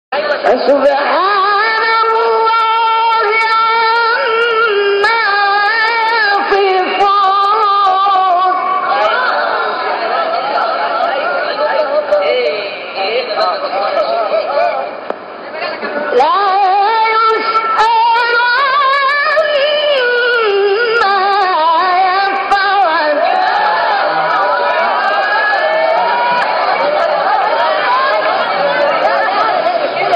گروه شبکه اجتماعی: فرازهای صوتی از تلاوت قاریان به‌نام مصری را می‌شنوید.
فرازی از محمدعبدالعزیزحصان در مقام حجاز/ سوره انبیا